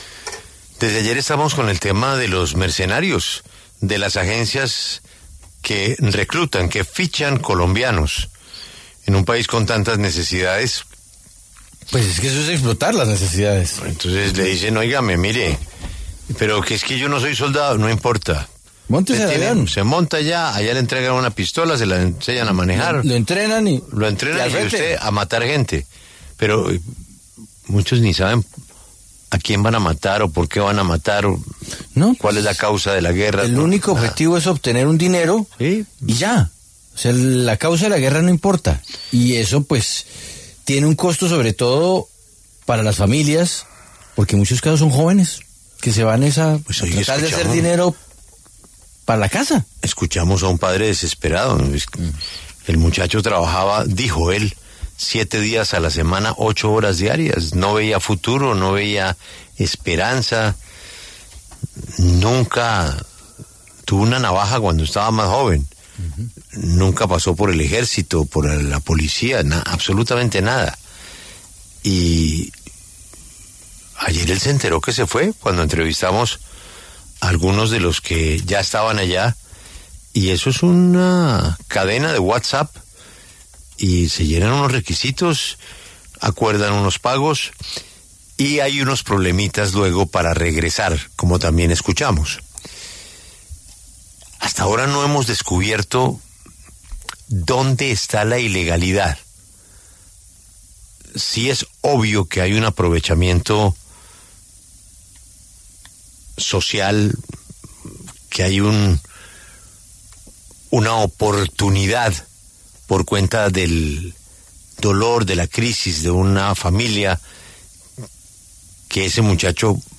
Los representantes Alirio Uribe, del Pacto Histórico, y Jhon Jairo Berrio, del Centro Democrático, pasaron por los micrófonos de La W.